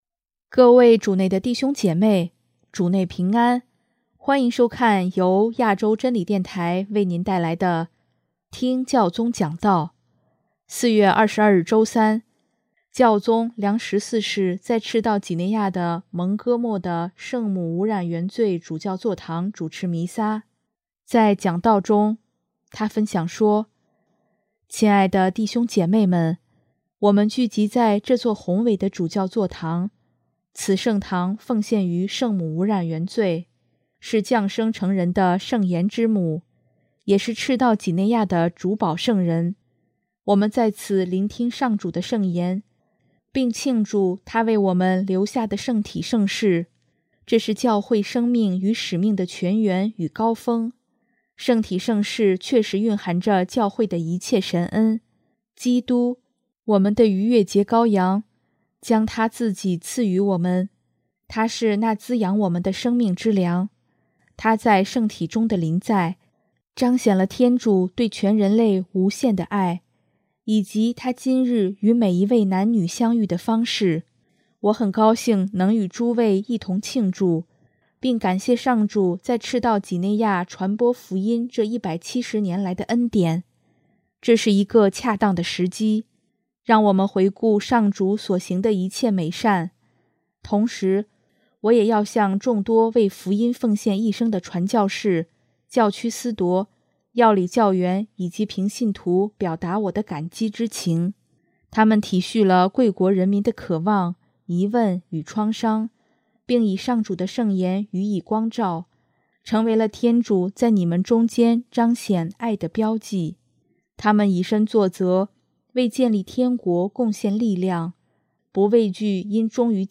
【听教宗讲道】|迈向充满希望的未来
4月22日周三，教宗良十四世在赤道几内亚的蒙戈莫（Mongomo）的圣母无染原罪主教座堂主持弥撒，在讲道中，他分享说：